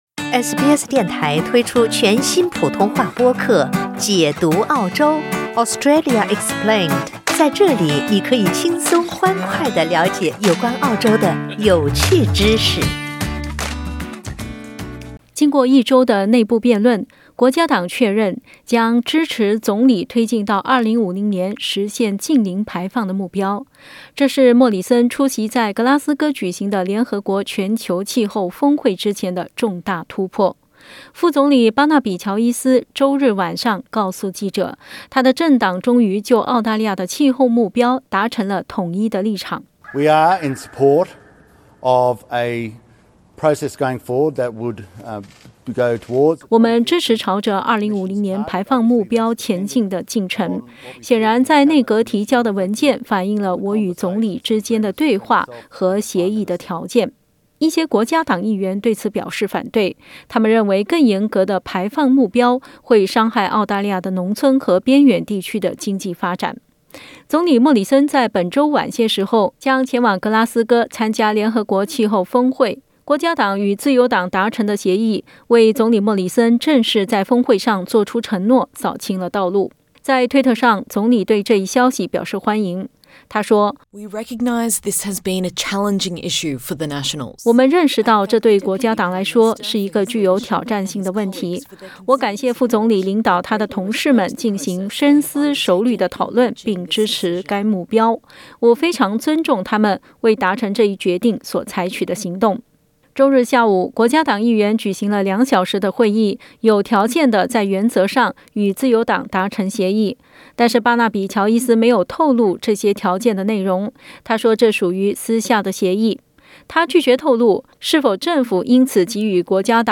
副總理巴納比.喬伊斯（Barnaby Joyce）宣布，國家黨終於與自由黨就澳大利亞的氣候目標達成一致。（點擊圖片收聽報道）